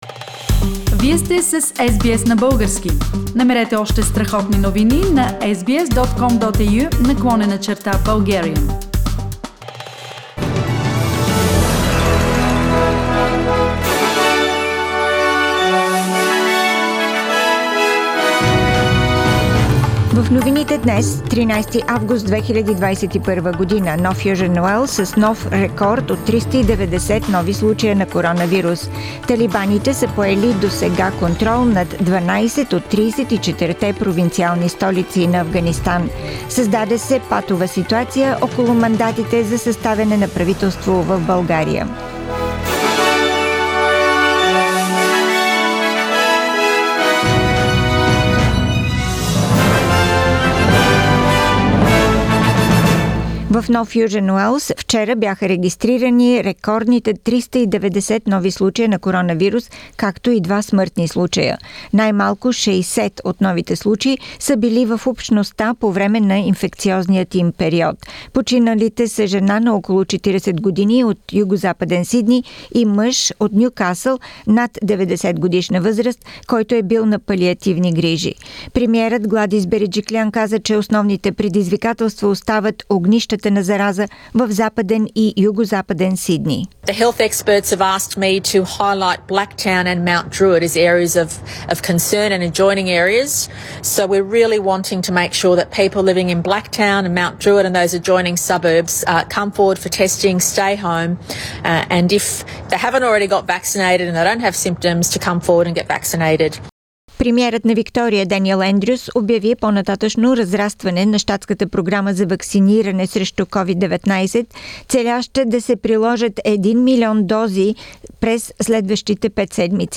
Weekly Bulgarian News – 13th August 2021